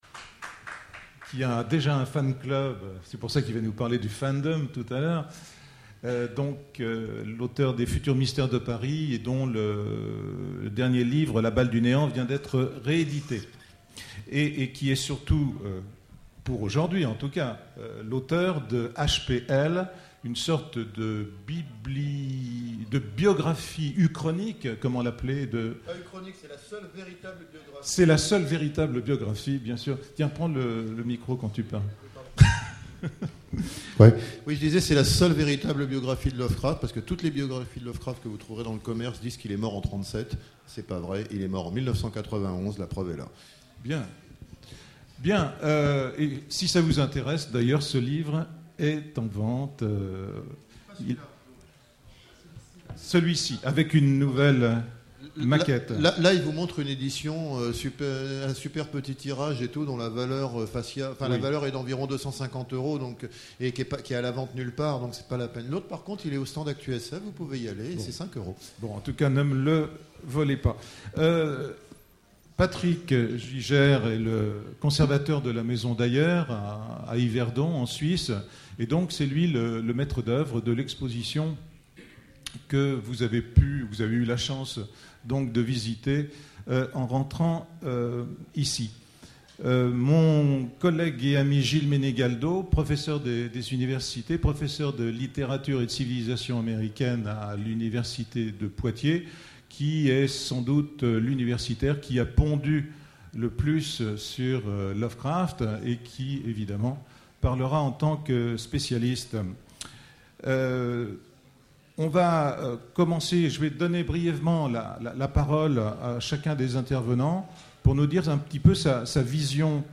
Voici l'enregistrement de la conférence "Lovecraft et les réseaux" aux Utopiales 2008